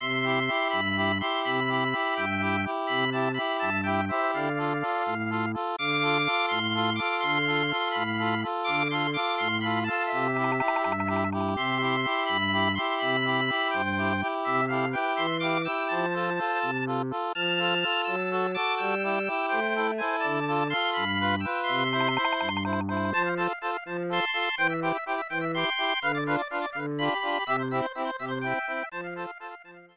Clipped to 30 seconds and applied fade-out.